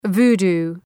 {‘vu:du:}
voodoo.mp3